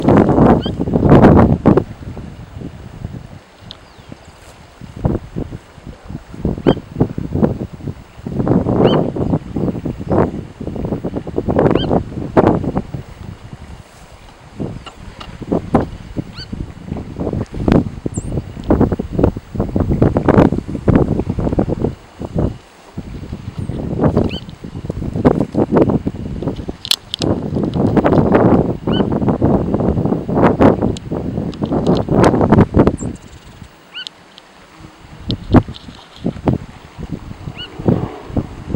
White-browed Tapaculo (Scytalopus superciliaris)
Spanish Name: Churrín Ceja Blanca
Location or protected area: El Infiernillo
Condition: Wild
Certainty: Recorded vocal